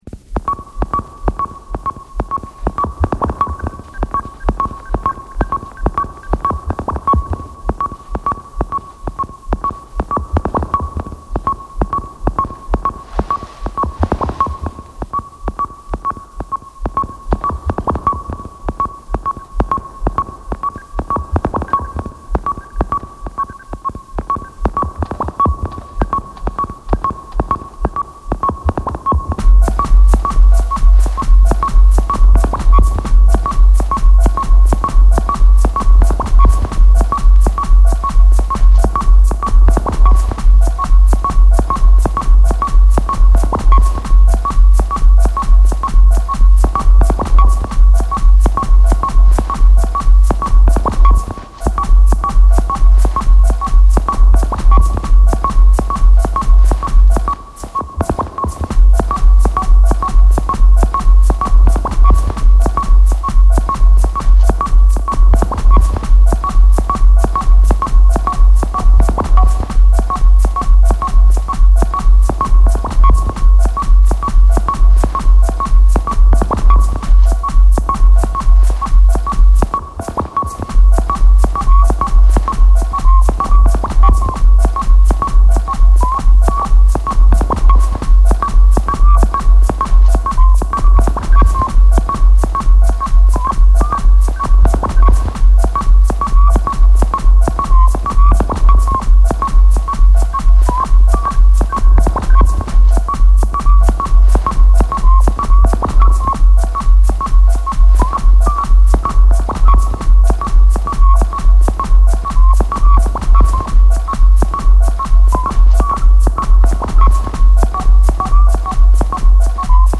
テクノミニマル
電子的な宇宙に放り出される